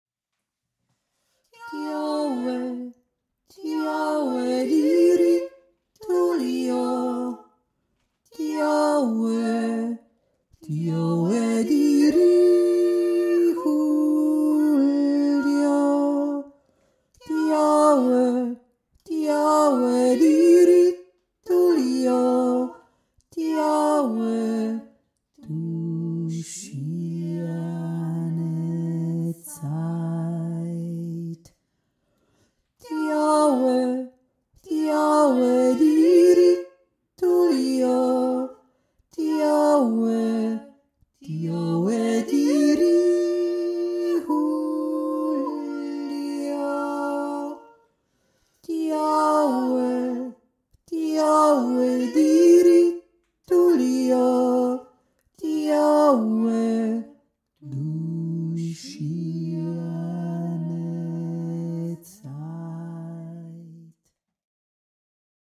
3. Stinme
aiblinger-weise-tiefe-stimme.mp3